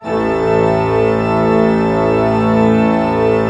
Index of /90_sSampleCDs/Propeller Island - Cathedral Organ/Partition F/PED.V.WERK M